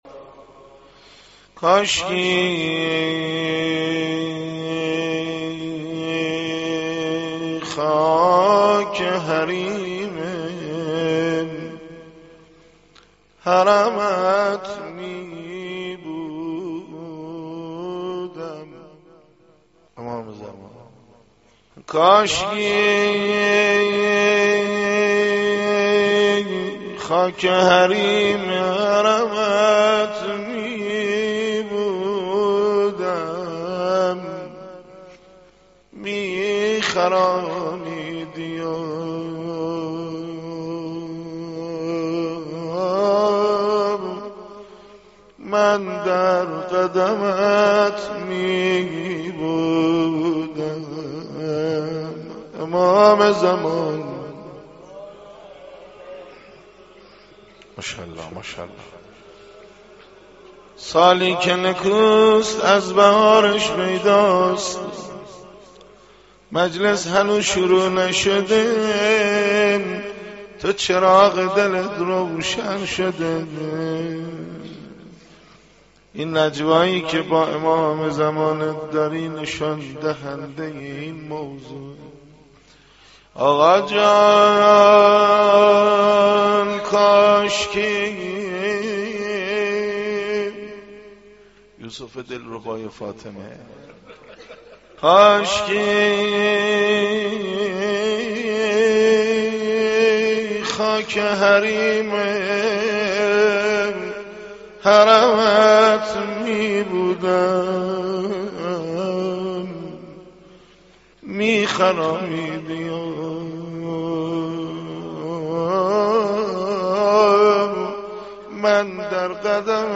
مناجات با امام زمان(عج)